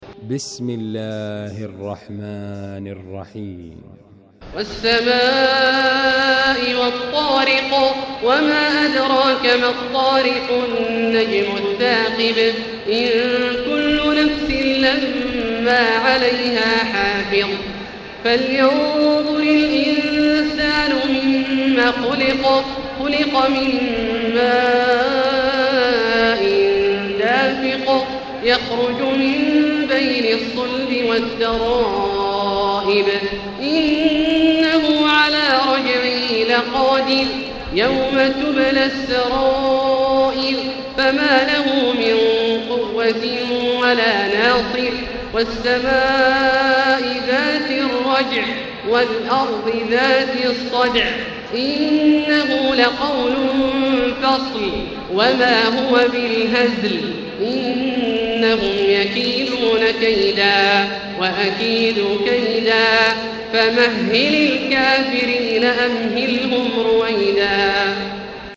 المكان: المسجد الحرام الشيخ: فضيلة الشيخ عبدالله الجهني فضيلة الشيخ عبدالله الجهني الطارق